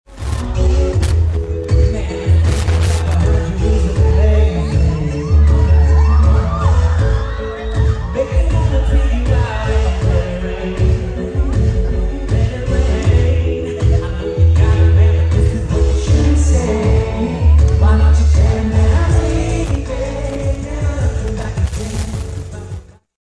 ‘A huge morale boost for campus:’ Jesse McCartney performs high-energy show at Emporia State’s Albert Taylor Hall
The weather forced a change in location, but it didn’t dampen the energy for Jesse McCartney’s concert at Emporia State on Friday.